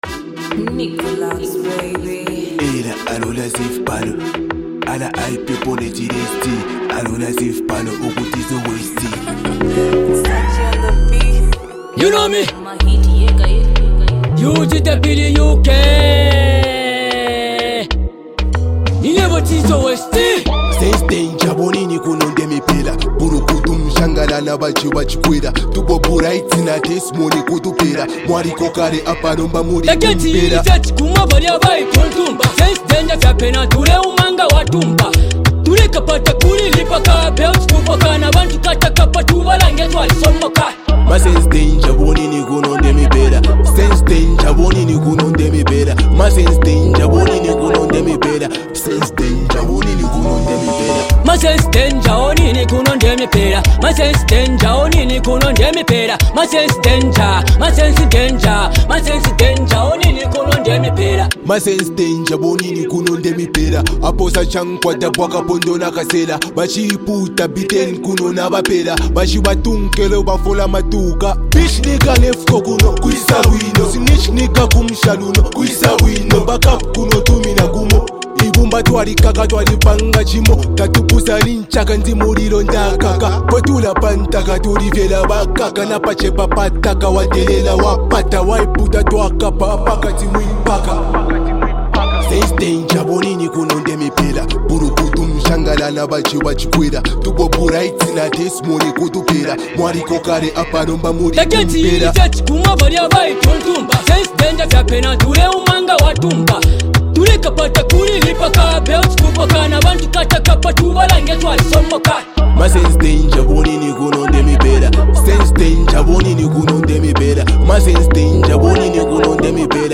is a hard-hitting, street-conscious track
delivers his verses with a calm but firm presence